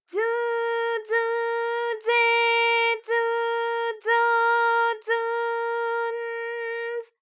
ALYS-DB-001-JPN - First Japanese UTAU vocal library of ALYS.
zu_zu_ze_zu_zo_zu_n_z.wav